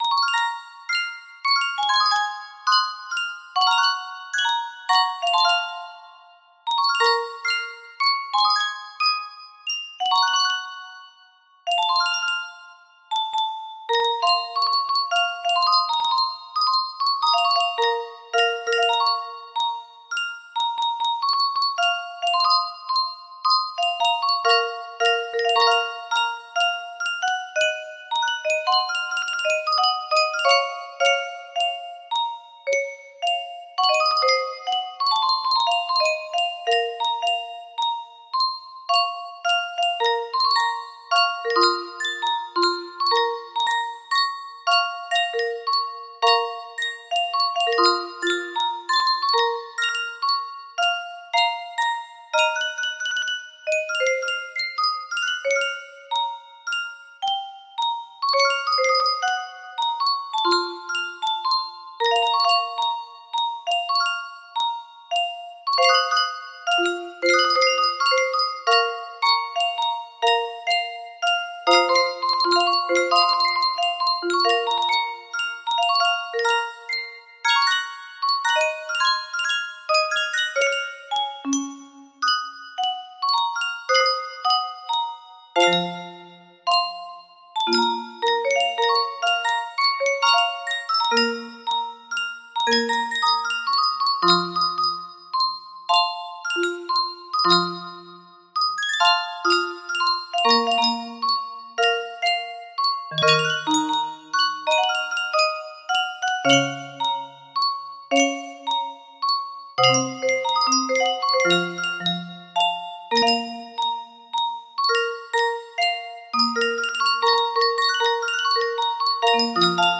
Whispers of Dusk music box melody
Full range 60